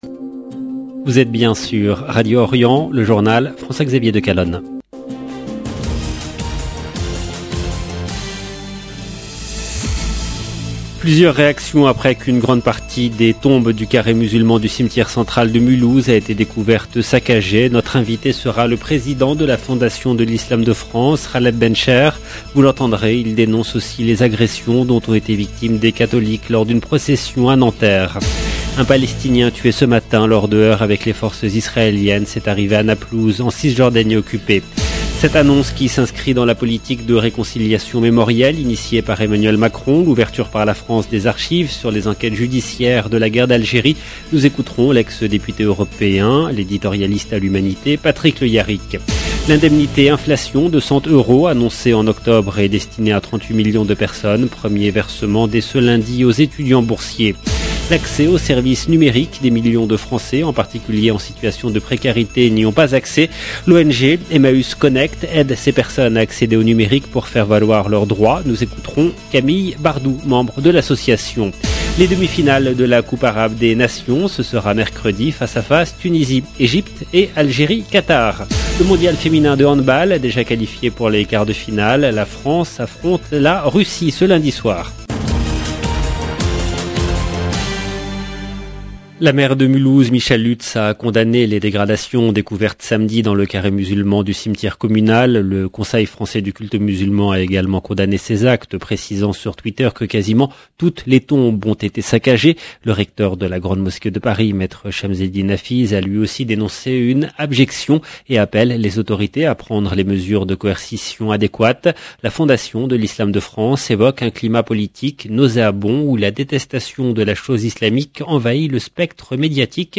LE JOURNAL DU SOIR EN LANGUE FRANCAISE DU 13/12/21
Algérie football précarité islam Mulhouse 13 décembre 2021 - 15 min 44 sec LE JOURNAL DU SOIR EN LANGUE FRANCAISE DU 13/12/21 LB JOURNAL EN LANGUE FRANÇAISE Plusieurs réactions après qu’une grande partie des tombes du carré musulman du cimetière central de Muhouse a été découverte "saccagée". Notre invité sera le président de la Fondation de l’islam de France Ghaleb Bencheikh.